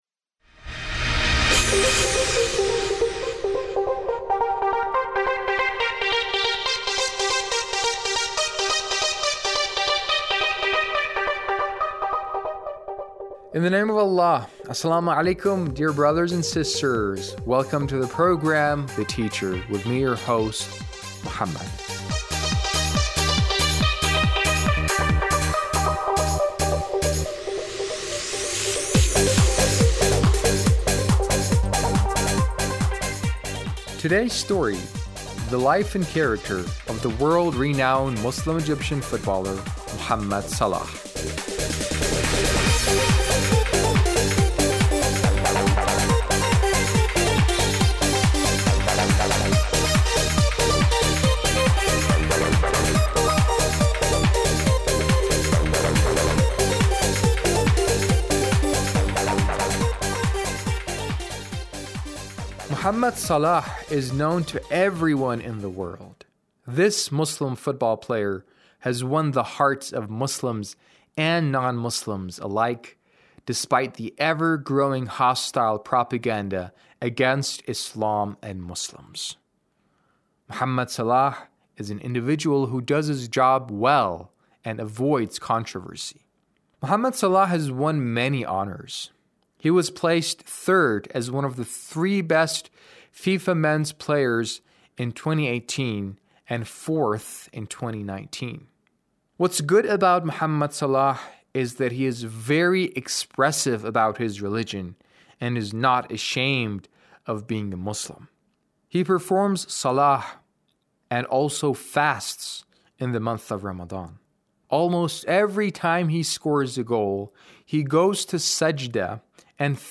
A radio documentary on the life of Mohamed Salah - 3